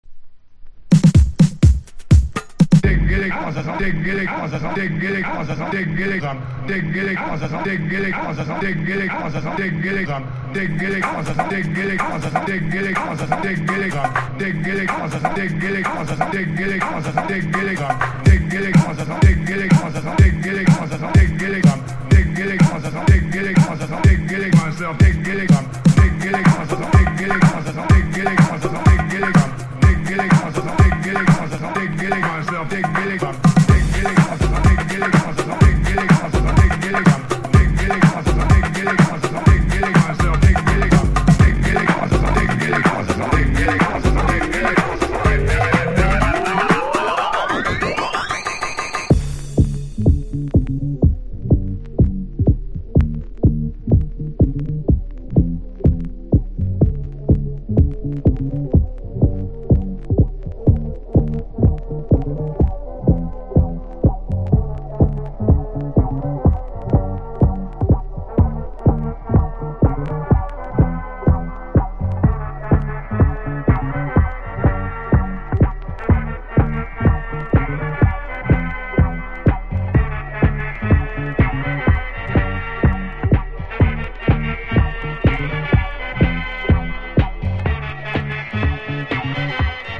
生音ファンキーチューン！